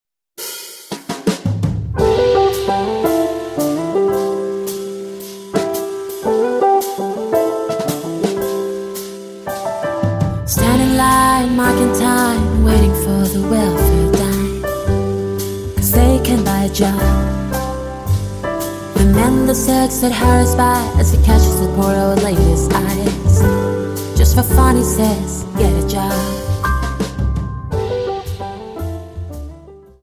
Dance: Slowfox 28 Song